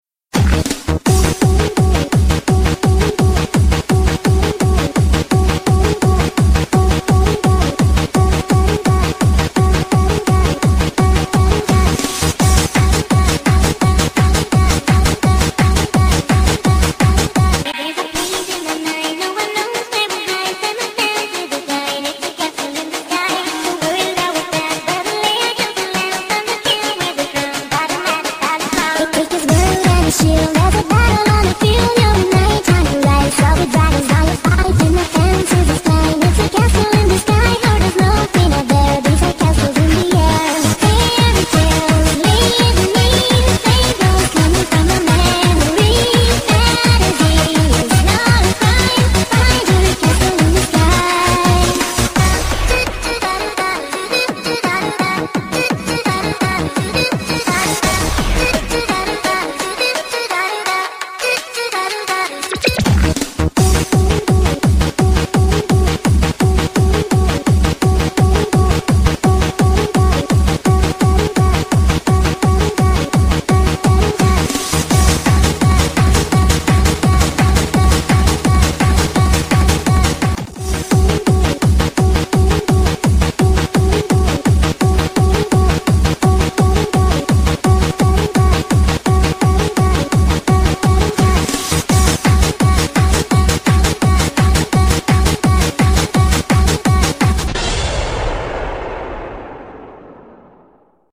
BPM169-170
Audio QualityPerfect (High Quality)
Comments[Fantasy Nightcore